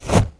item_pickup_swipe_01.wav